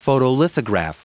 Prononciation du mot photolithograph en anglais (fichier audio)
photolithograph.wav